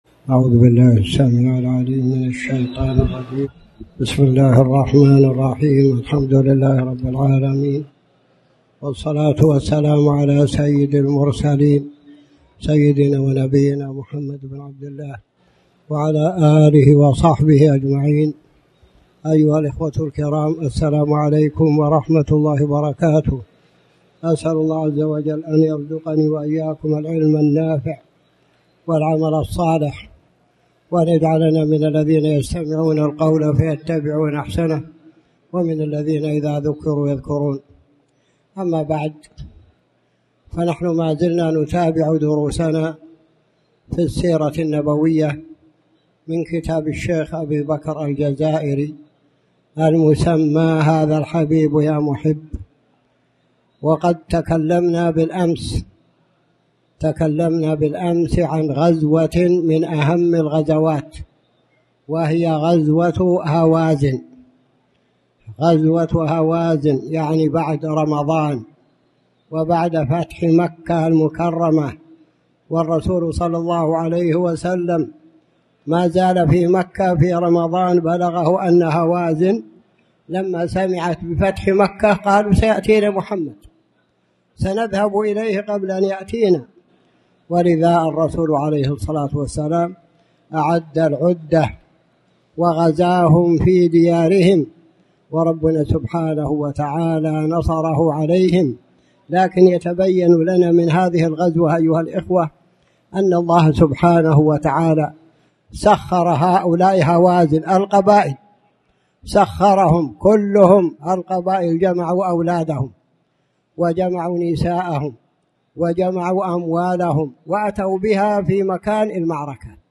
تاريخ النشر ٢٦ محرم ١٤٣٩ هـ المكان: المسجد الحرام الشيخ